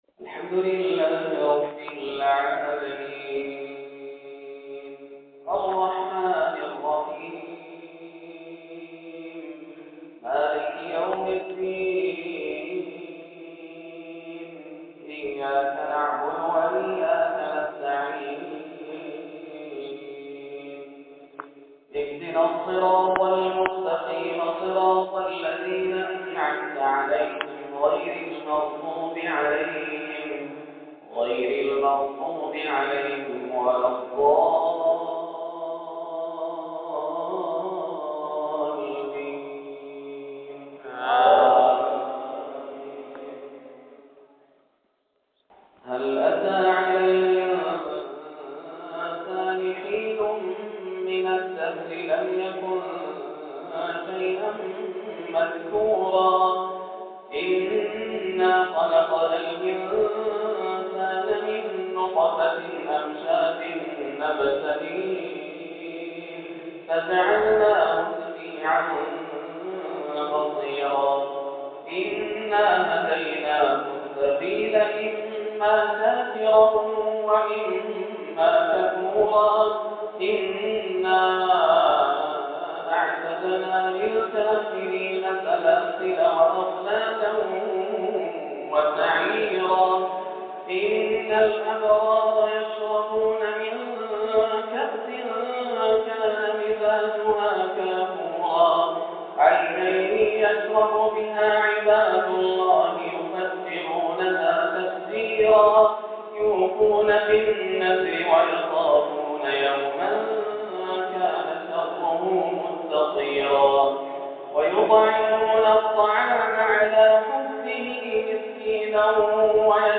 سورة الإنسان - من أجمل الفجريات > عام 1430 > الفروض - تلاوات ياسر الدوسري